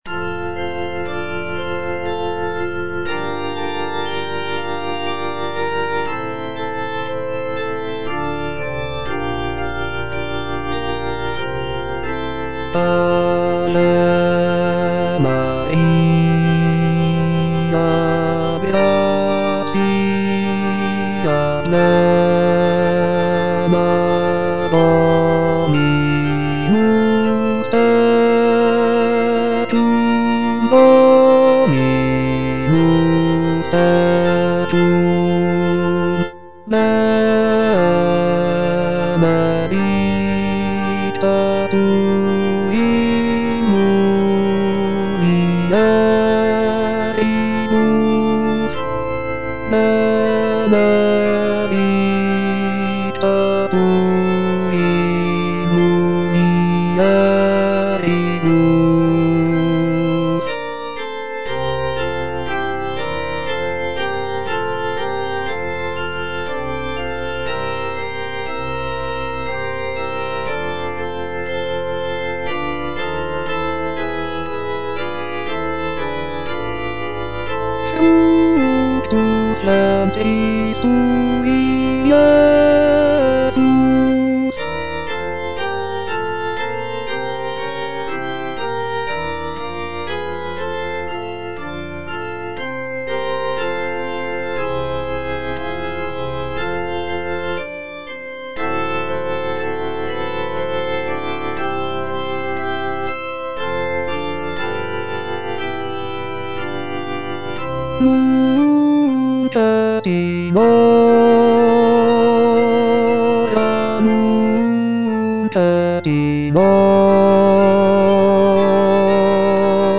Bass (Voice with metronome)